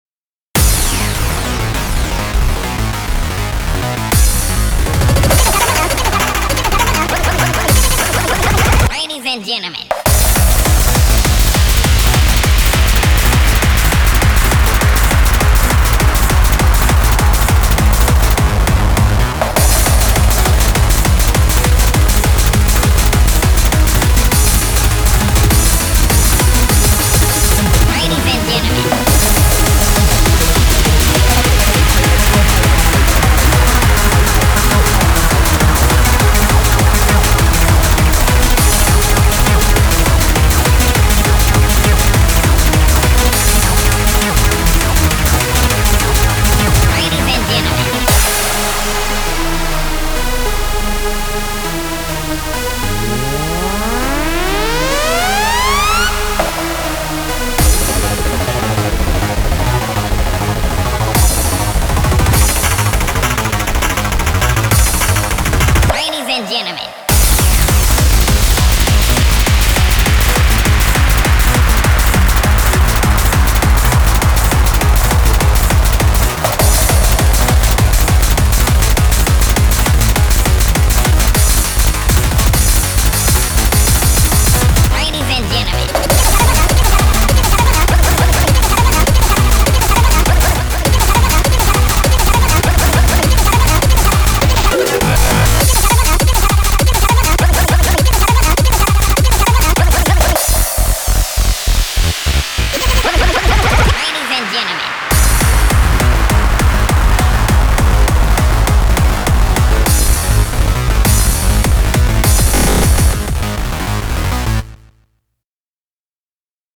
BPM202
Audio QualityPerfect (High Quality)
Genre: MASSIVE DANCE SPEED.